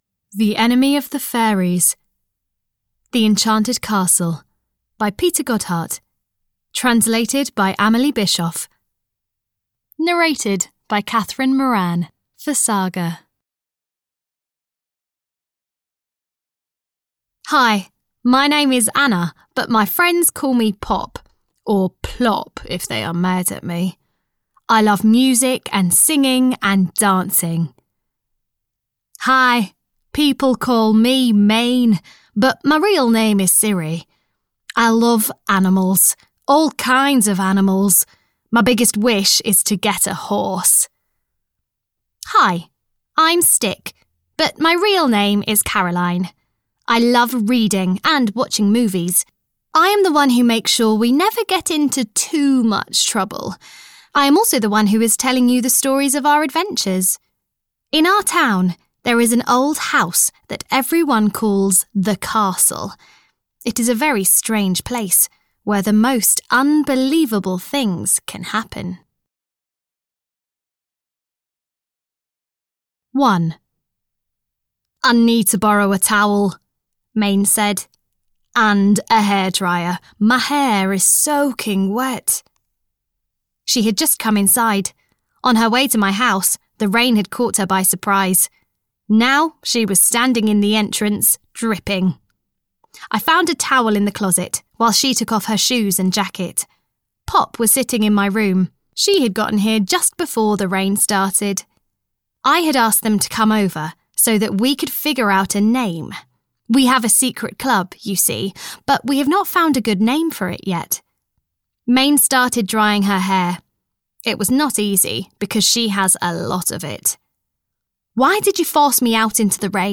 Audio knihaThe Enchanted Castle 3 - The Enemy of the Fairies (EN)
Ukázka z knihy